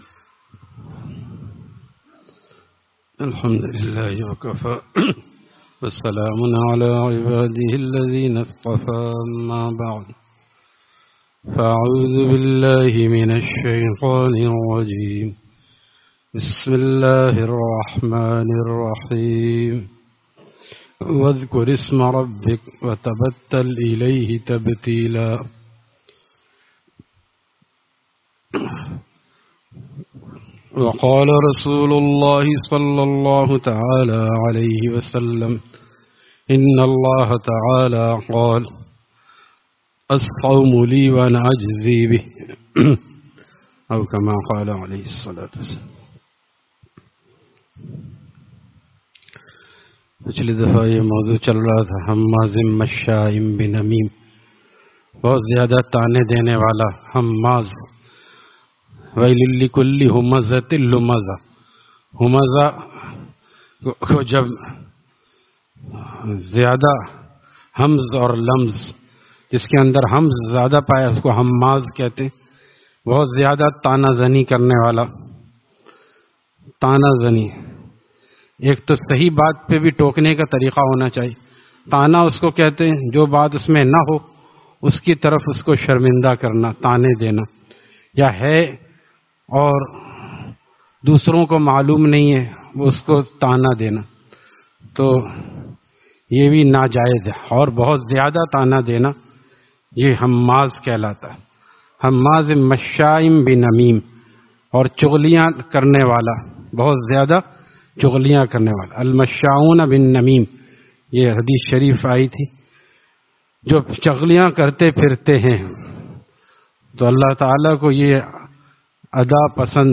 Friday Markazi Bayan at Jama Masjid Gulzar e Muhammadi, Khanqah Gulzar e Akhter, Sec 4D, Surjani Town